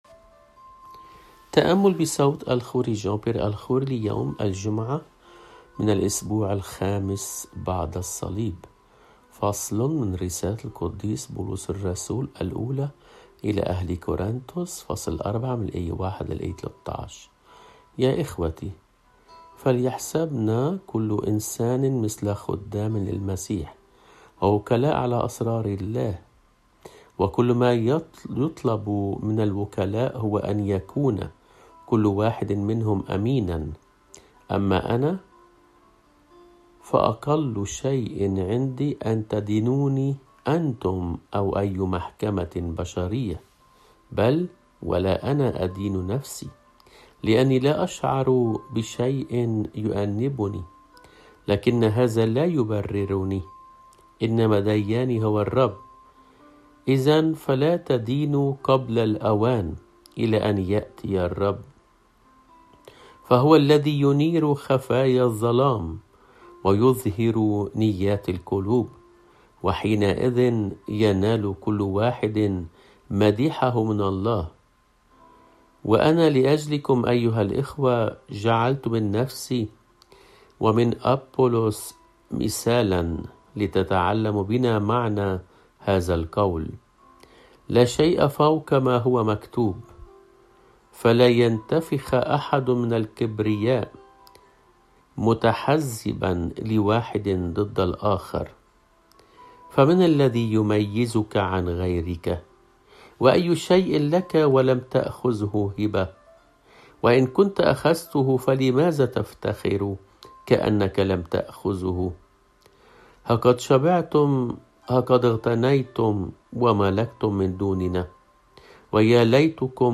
قراءات روحيّة صوتيّة - Yasou3na